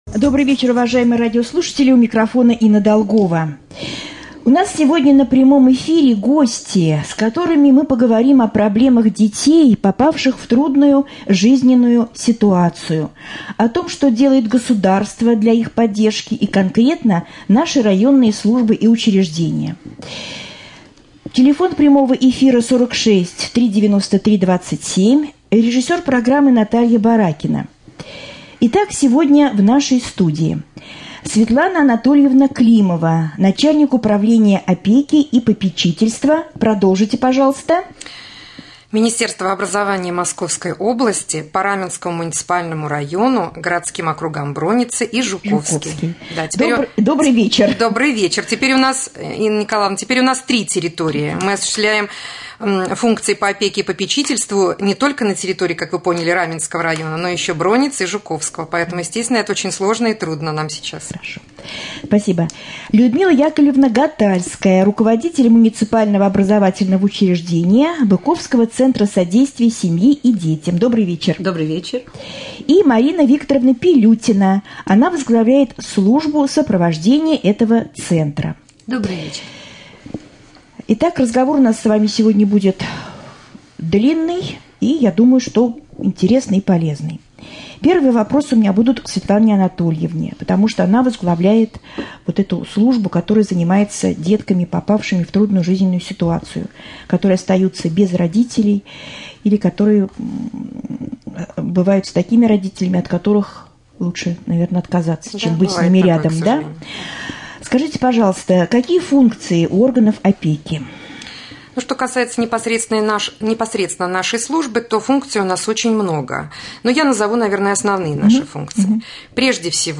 Новости 2.